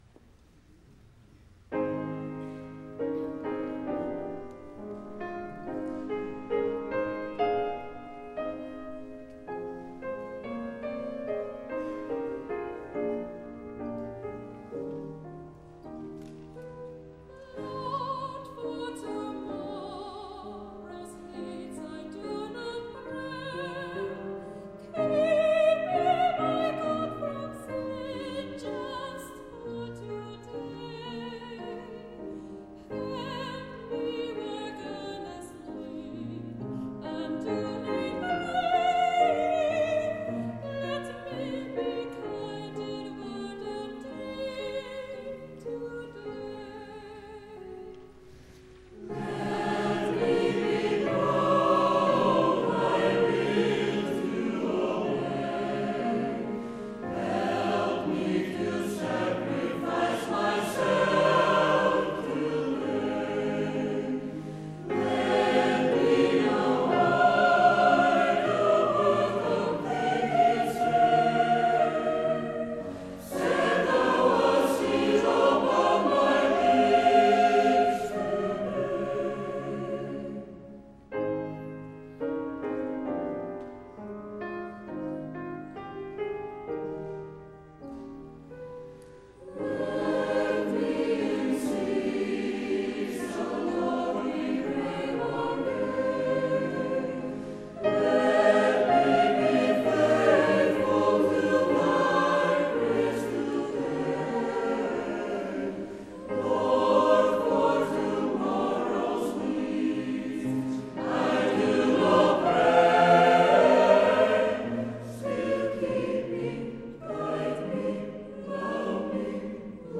Upptøkurnar eru frá konsert í Samuelskirkjuni í Keypmannahavn á páskum í 2002.